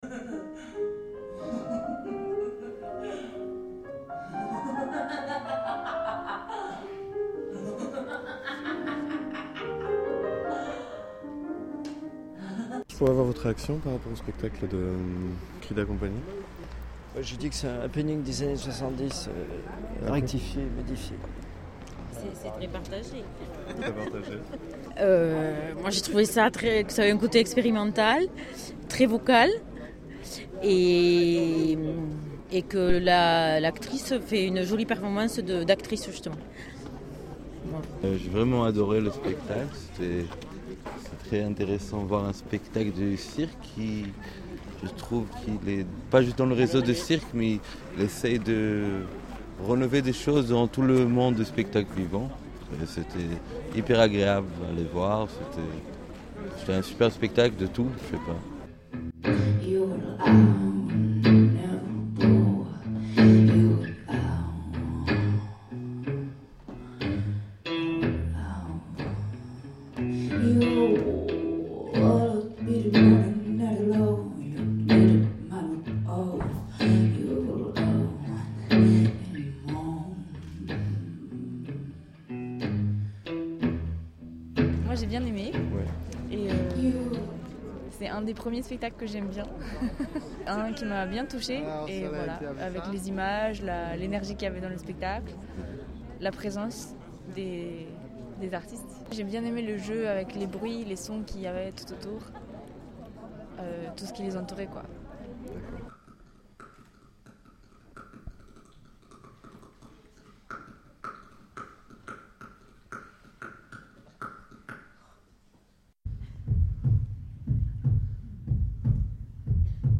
Sortie du spectacle Aie de Cridacompany – Ruchemania
Sortie du spectacle Aie de Cridacompany Documents joints Sortie_Aie_Cridacompany.mp3 Tagged reportages 2010 Laisser un commentaire Annuler la réponse Votre adresse e-mail ne sera pas publiée.